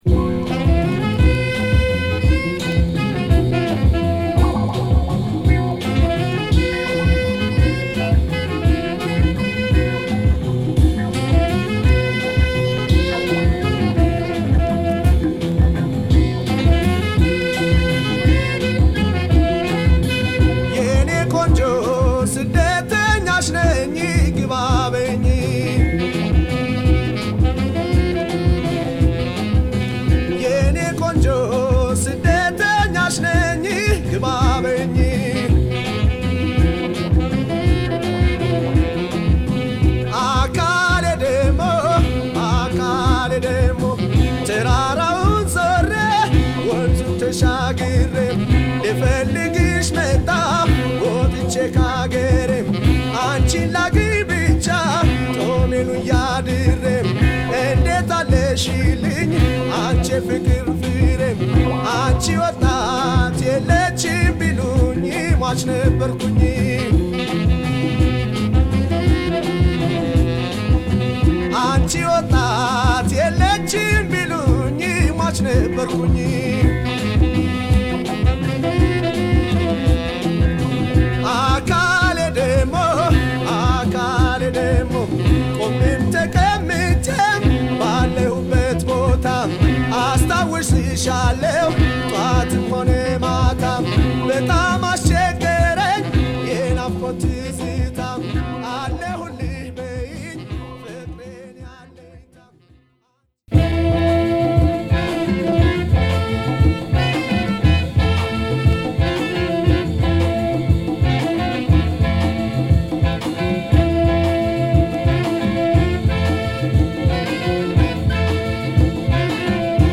独特のエチオグルーヴが炸裂する1枚！